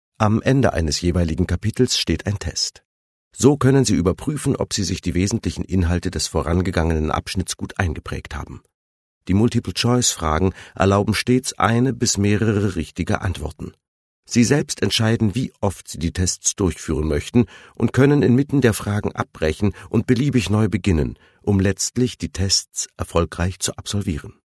warme Stimme, Stimmlage Bariton, sehr flexibel
Sprechprobe: eLearning (Muttersprache):